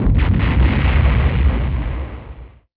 boom16.wav